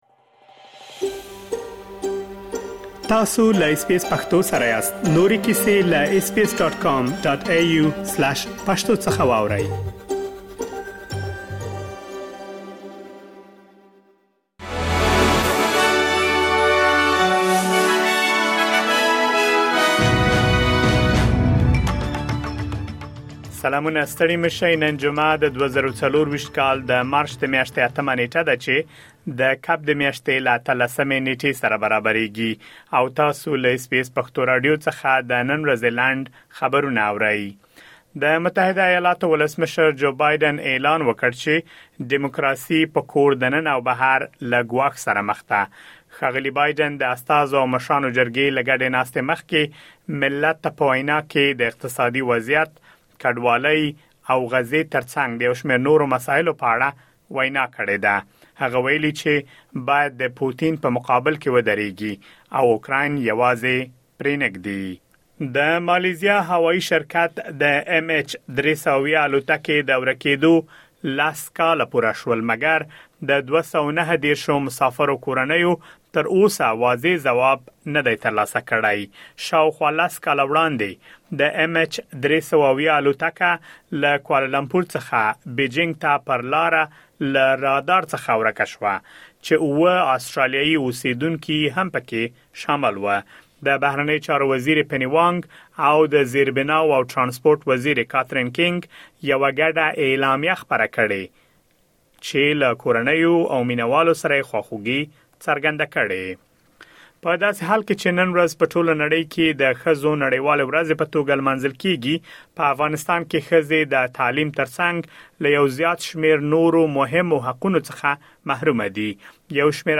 د اس بي اس پښتو راډیو د نن ورځې لنډ خبرونه دلته واورئ.